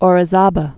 (ôrĭ-zäbə, ōrē-sävä), Pico de or Ci·tlal·té·petl (sētläl-tāpĕt-l)